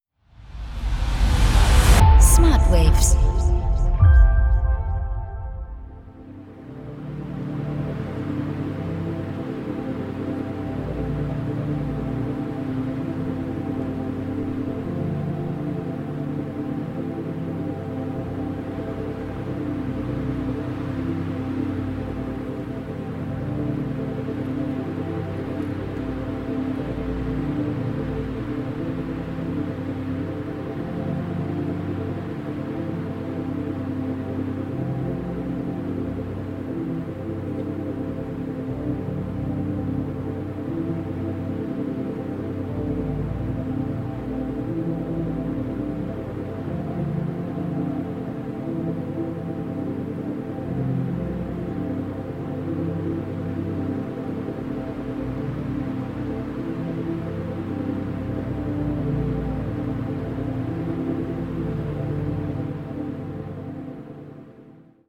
Binaurale & Isochrone Beats